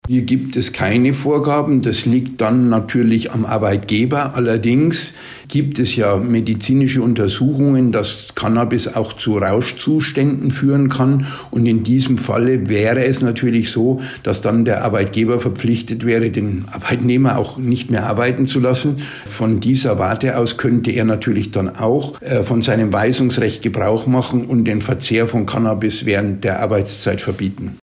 Interview: Cannabis-Teillegalisierung - das sagt die DEHOGA - PRIMATON